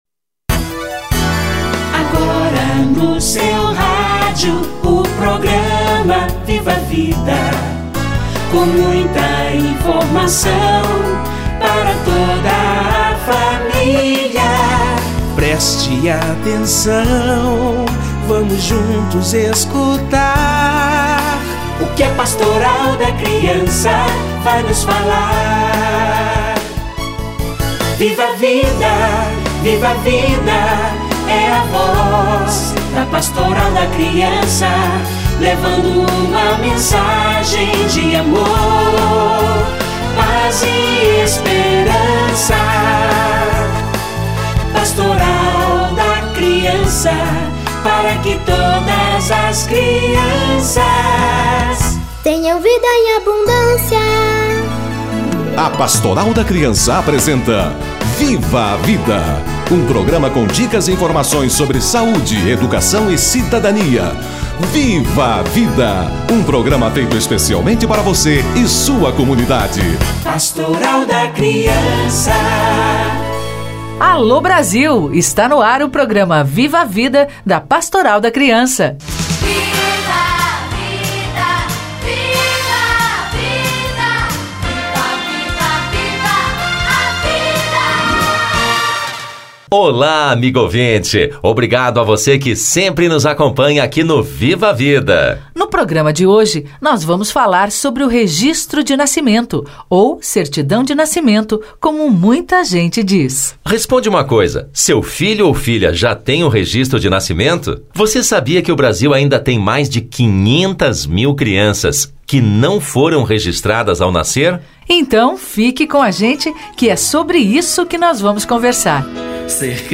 Registro de nascimento - Entrevista